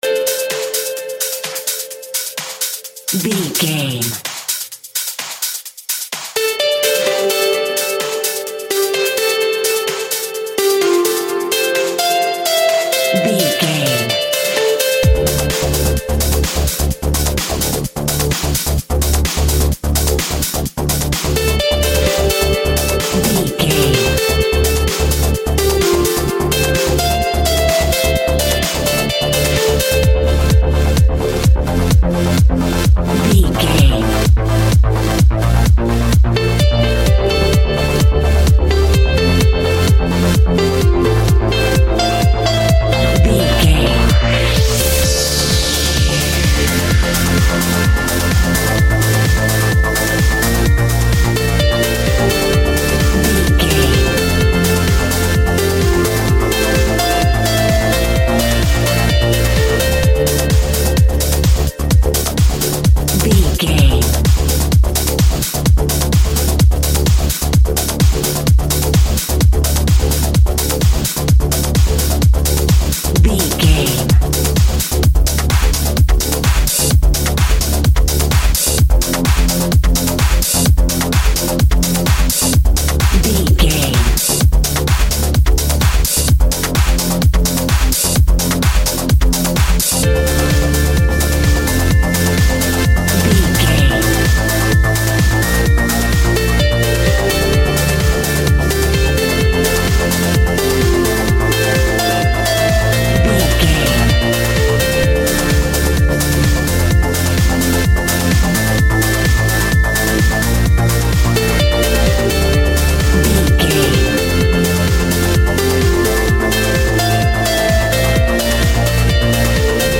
Aeolian/Minor
D
Fast
groovy
futuristic
uplifting
drum machine
electric guitar
synthesiser
house
electro dance
techno
trance
synth bass
upbeat